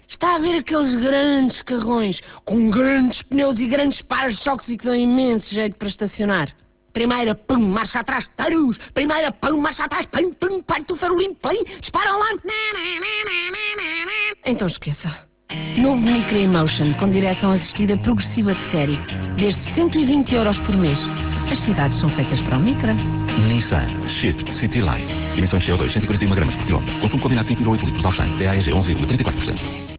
clique para ouvir o spot). Esta campanha passa na RFM e têm 2 spots diferentes que já passaram 91 vezes até ao dia 17 de Fevereiro tendo totalizado 50 582 euros, a preço tabela.